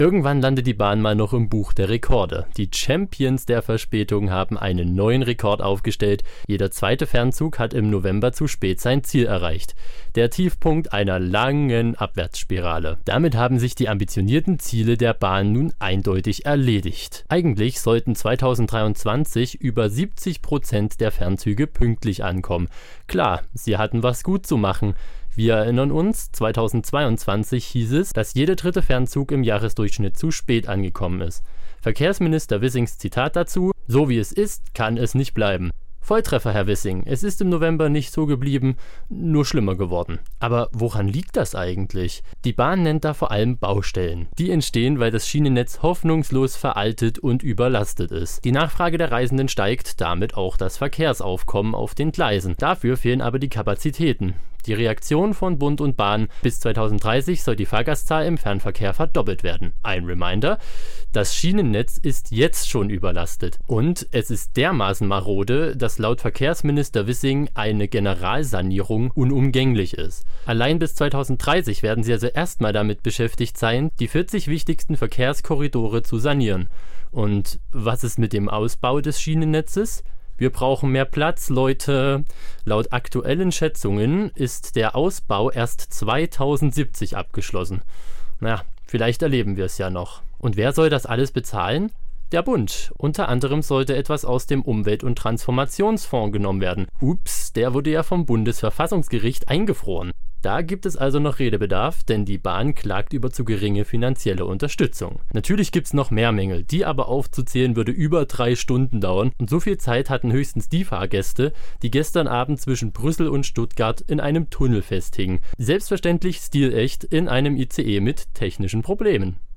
Reif für das Buch der Rekorde: die Deutsche Bahn - ein Kommentar